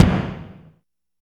12 AMB KIK-L.wav